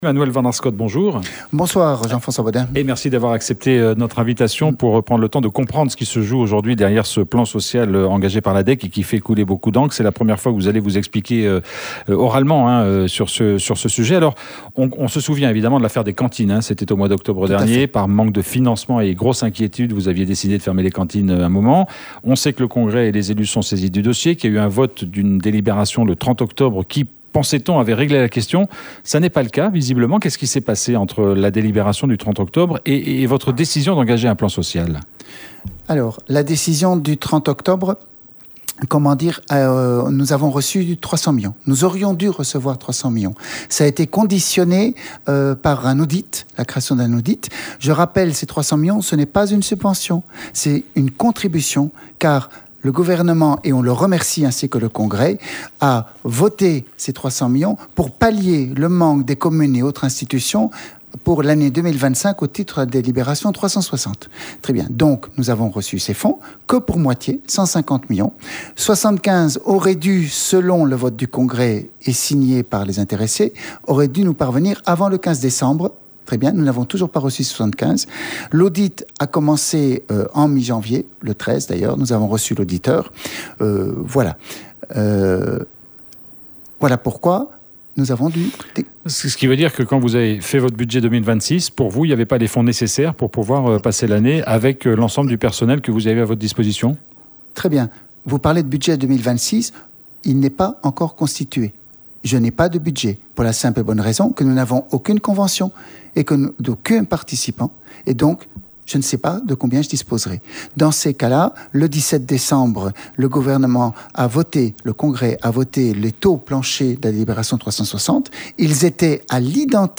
Il était l'invité de notre journal de 17h45.